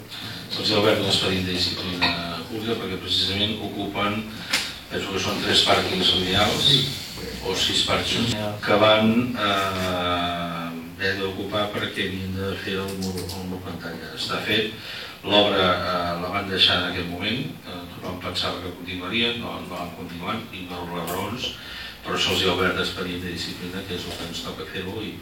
L’expedient de disciplina s’ha obert perquè ocupen sis pàrquings lineals i no es treballa. Aquestes han estat les paraules del regidor d’Esquerra Republicana, Miquel Bell-lloch.